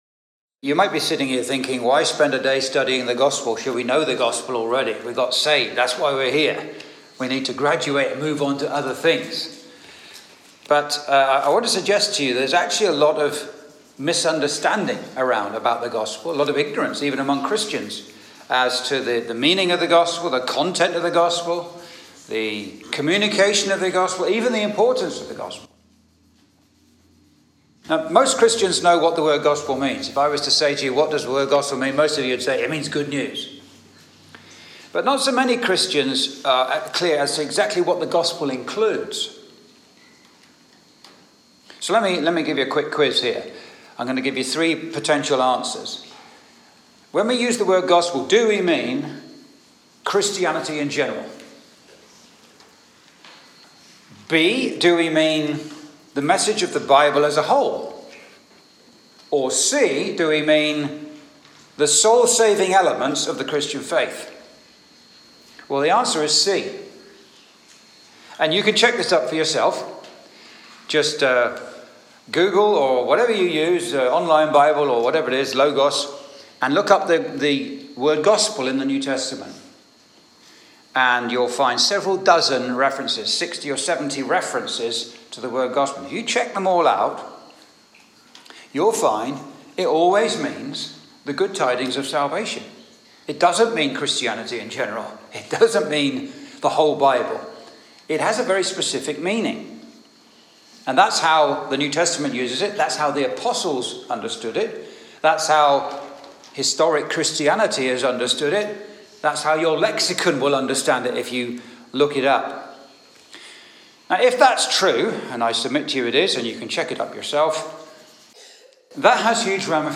(Recorded in Bury St Edmunds Gospel Hall, Suffolk, UK, 11th May 2024)